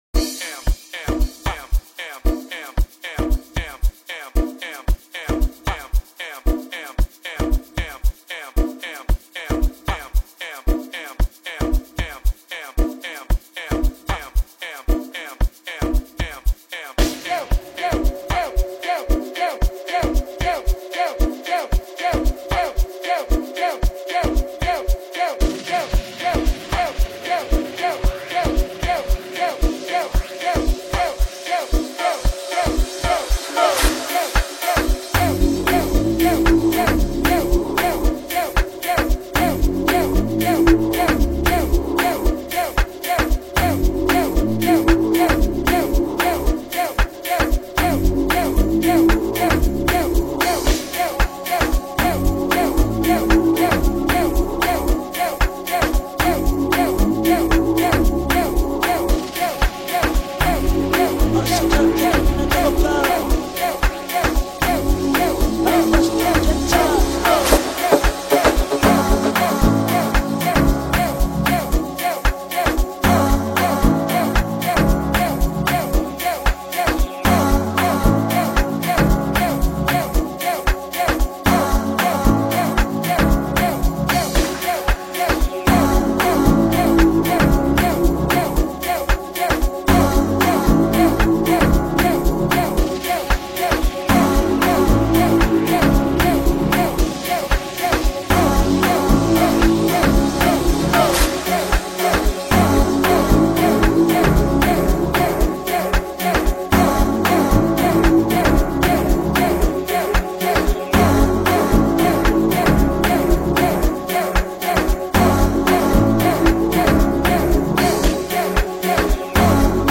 Destined for the clubs and the charts
uplifting, banging and refreshing